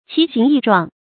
奇形異狀 注音： ㄑㄧˊ ㄒㄧㄥˊ ㄧˋ ㄓㄨㄤˋ 讀音讀法： 意思解釋： 亦作「奇形怪狀」。